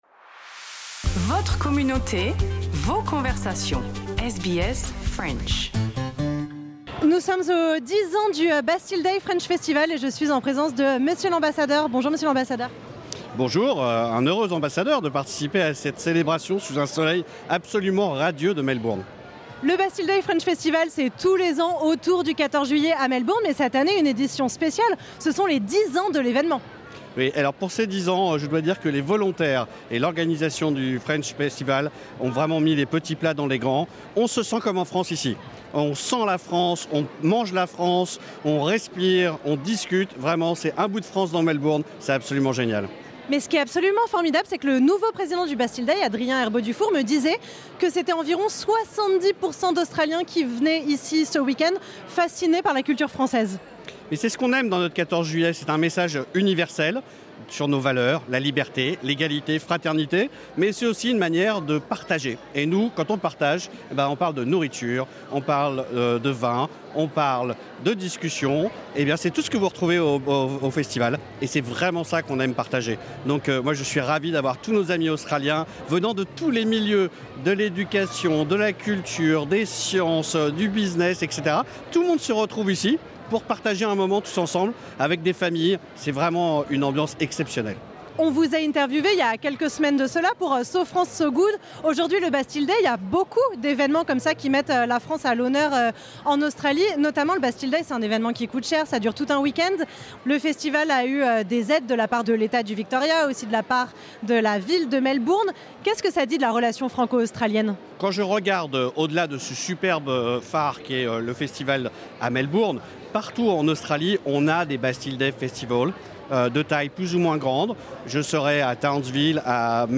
Entretien avec l’Ambassadeur de France en Australie, Pierre-André Imbert, à l’occasion du Bastille Day French Festival 2025.